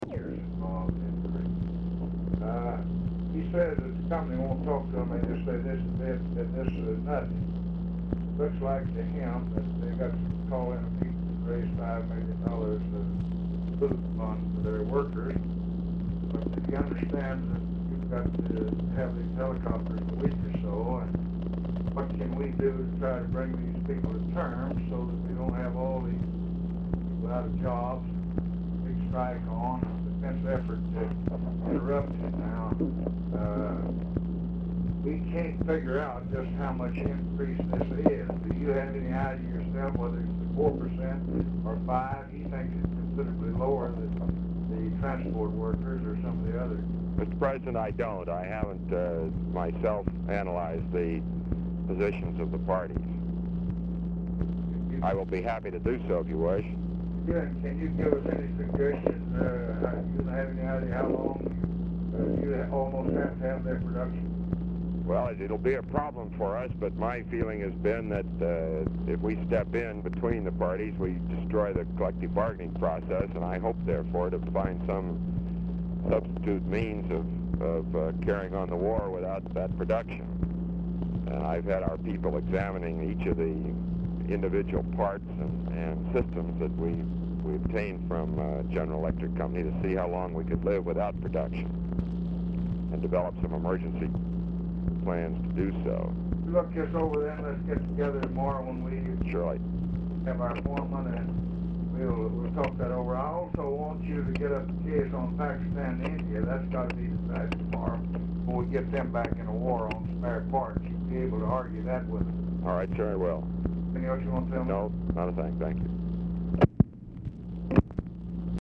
RECORDING STARTS AFTER CONVERSATION HAS BEGUN; POOR SOUND QUALITY; LBJ APPARENTLY ON SPEAKERPHONE; DAILY DIARY INDICATES LBJ IS MEETING WITH GEORGE MEANY, JOSEPH CALIFANO, GARDNER ACKLEY AT TIME OF CALL
Telephone conversation
Dictation belt